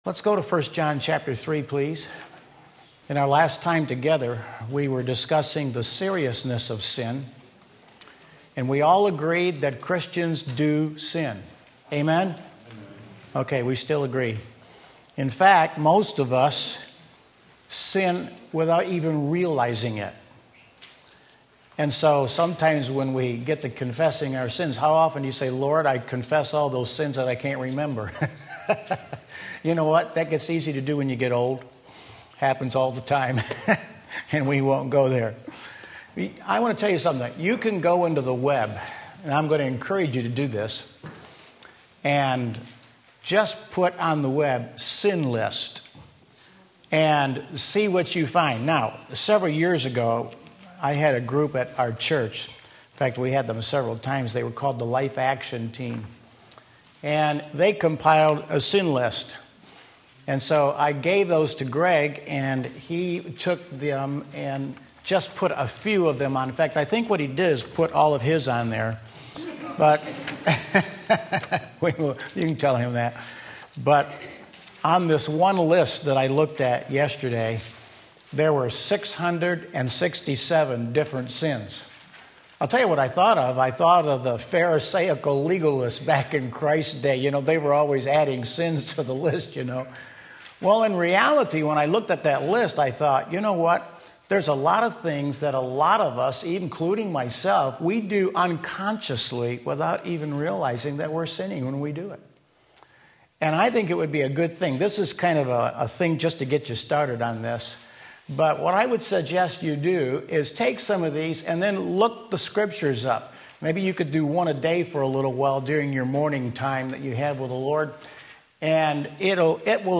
Sermon Link
Part 2 1 John 3 Sunday School